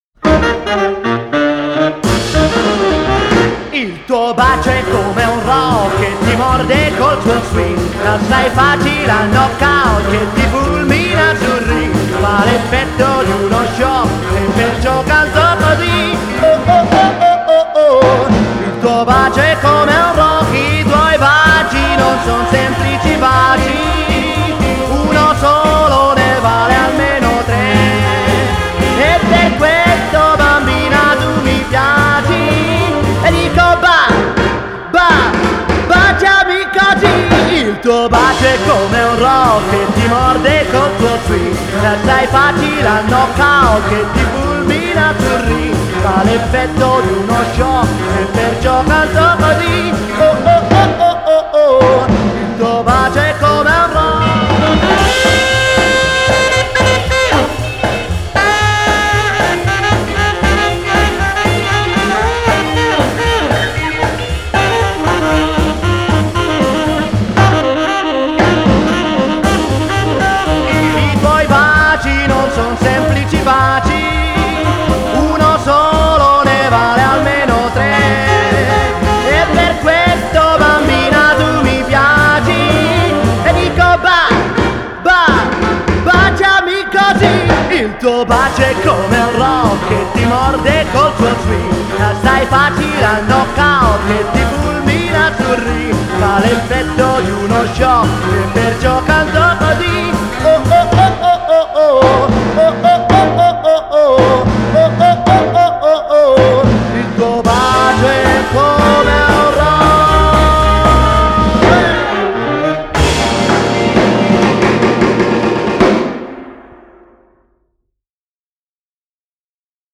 Genre: Pop, Jazz, Rock'n'roll